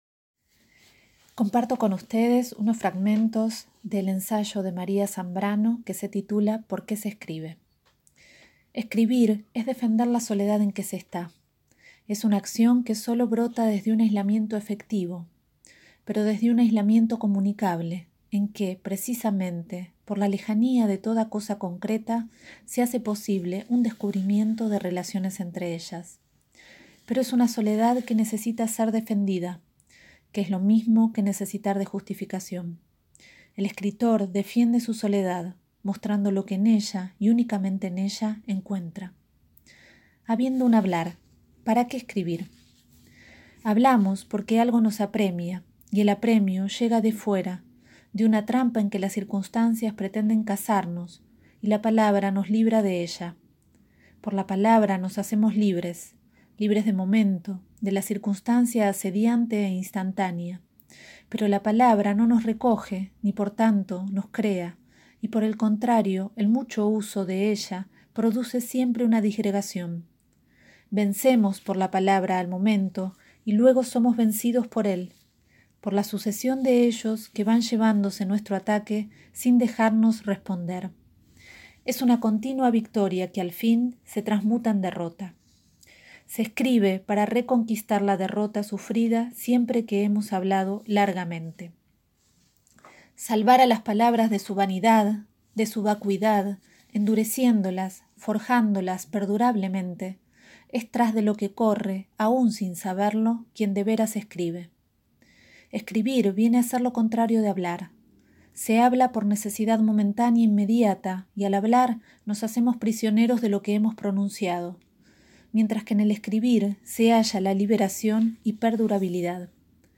la bella lectura